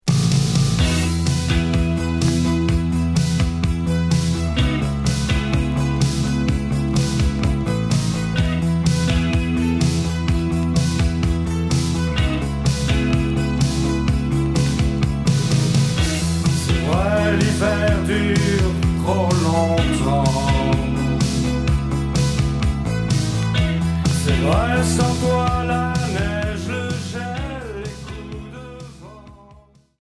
Rock Cold wave Deuxième 45t retour à l'accueil